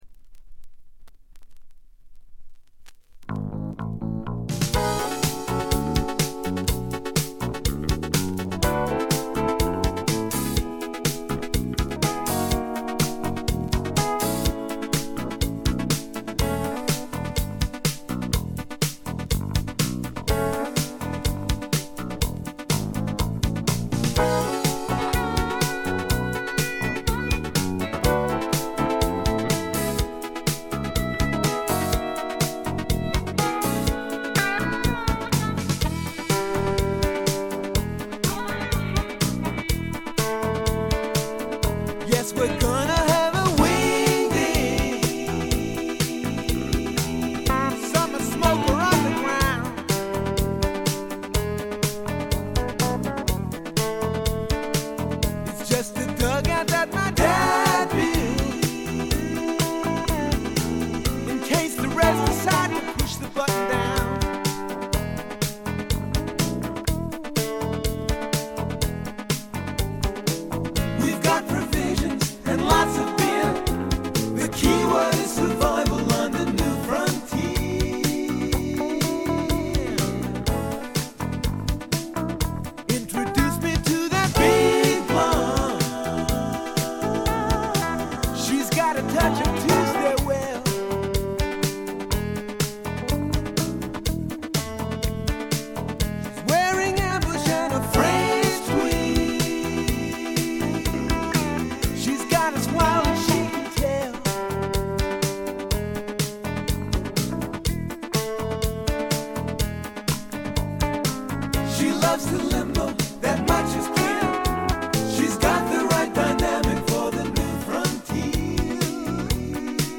ホーム > レコード：ポップ / AOR
部分試聴ですが、ごくわずかなノイズ感のみ。
試聴曲は現品からの取り込み音源です。